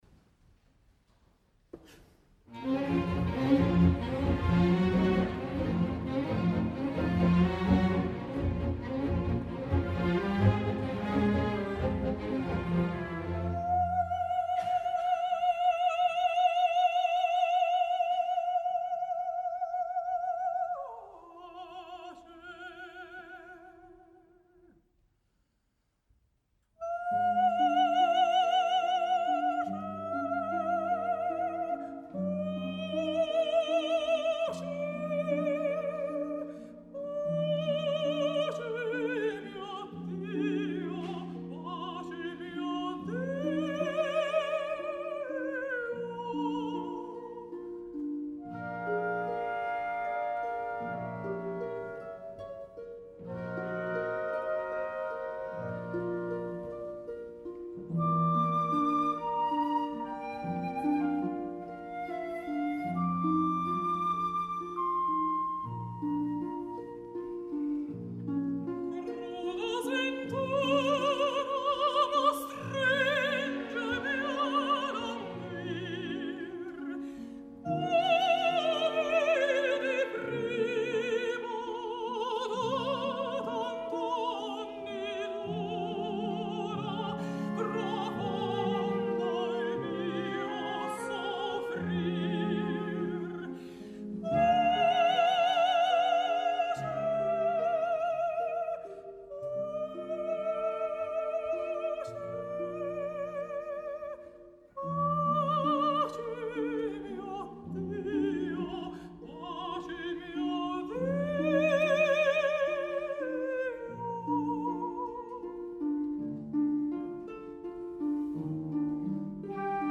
al Victoria Hall de Ginebra
L’estil és més belcantista